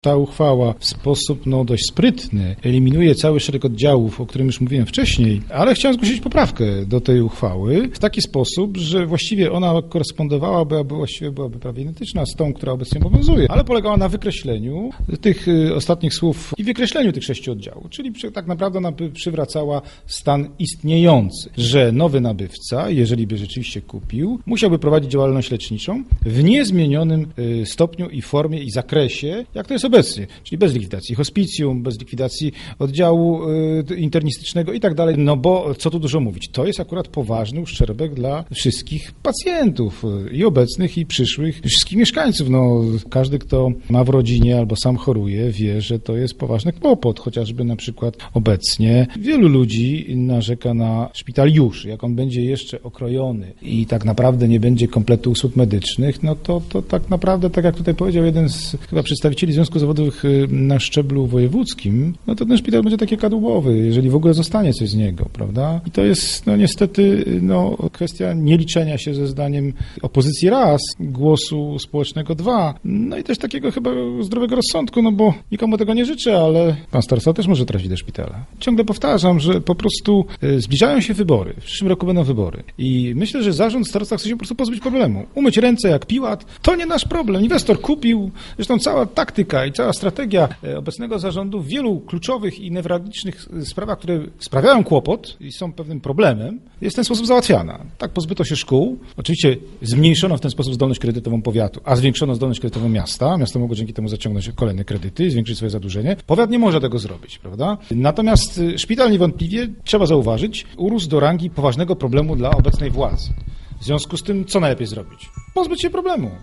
Mówi radny powiatu lubińskiego, Tadeusz Maćkała.